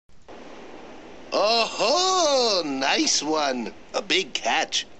Outside of Nay, they pull up the net, and we switch to another voice-acted cutscene.
Shiramine sounds like Steve Urkel impersonating Ringo Starr.